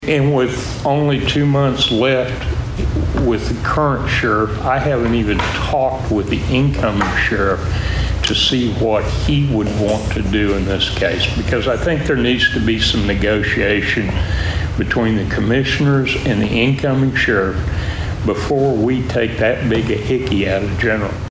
At Monday's Board of Osage County Commissioners meeting, there was discussion regarding a revised sheriff's office budget for the 2024-2025 fiscal year.